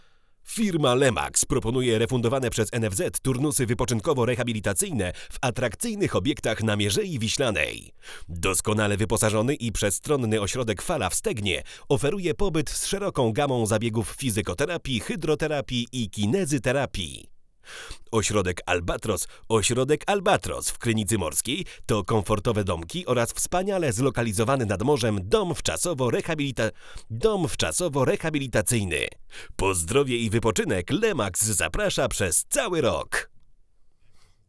Głos jest wyraźny, soczysty, czysty. Ale jednocześnie wzrosła wrażliwość na sybilanty, a braki w dolnym zakresie spowodowały, że wokale tracą indywidualne brzmienie.